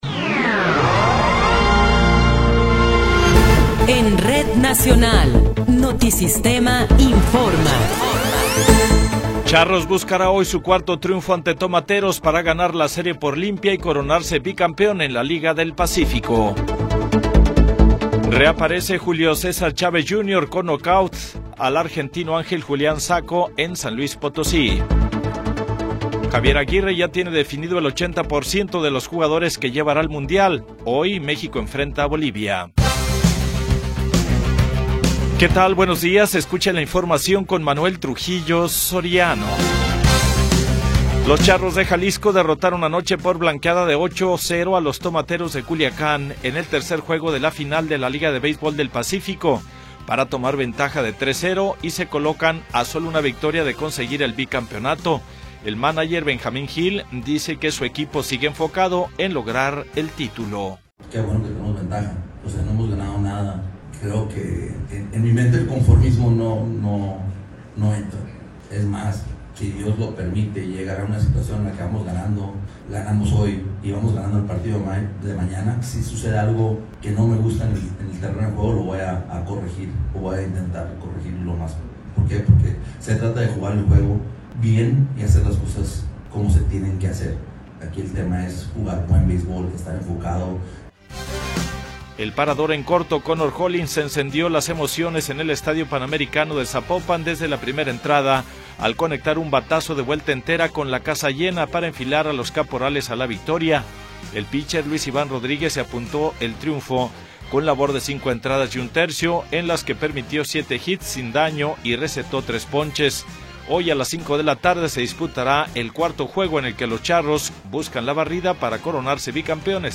Noticiero 9 hrs. – 25 de Enero de 2026
Resumen informativo Notisistema, la mejor y más completa información cada hora en la hora.